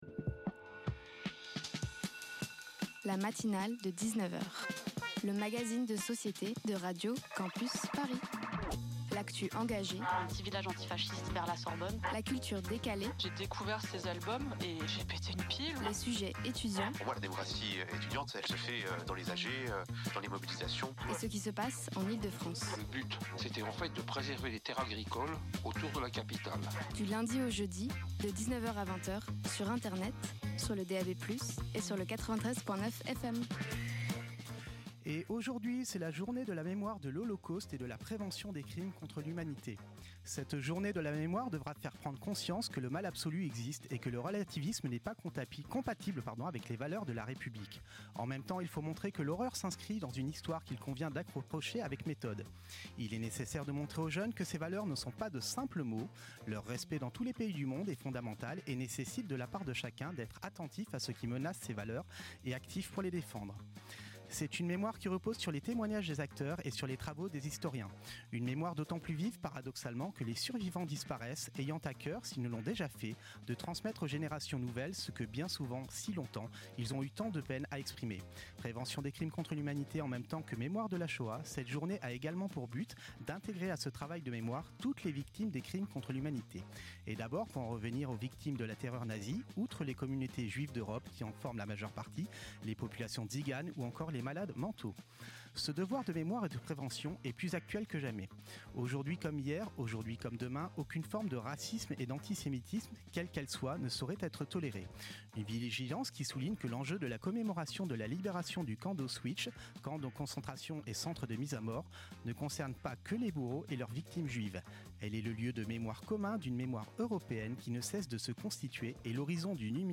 Les mouvements étudiants & le festival 160 000 Enfants Partager Type Magazine Société Culture mardi 27 janvier 2026 Lire Pause Télécharger Ce soir